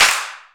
• Small Reverb Clap Sound F Key 06.wav
Royality free clap one shot - kick tuned to the F note. Loudest frequency: 3664Hz
small-reverb-clap-sound-f-key-06-rFd.wav